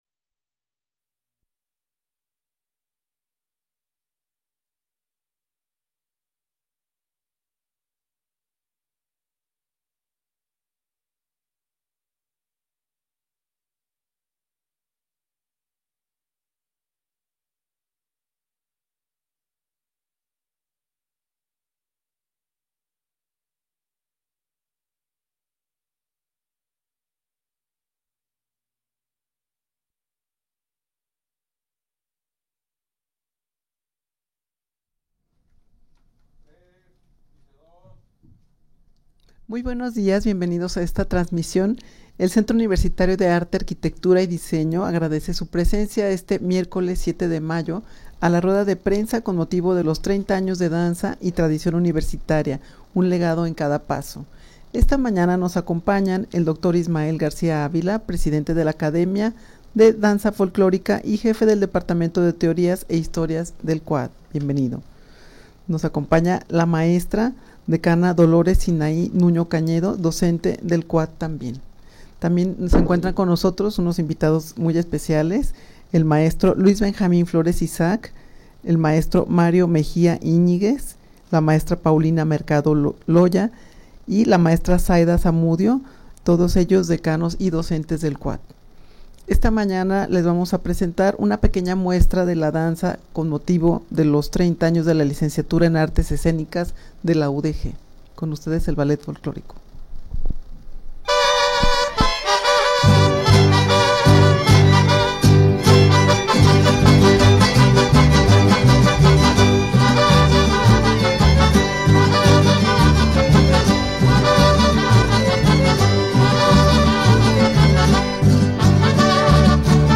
Audio de la Rueda de Prensa
rueda-de-prensa-30-anos-de-danza-y-tradicion-universitaria-un-legado-en-cada-paso.mp3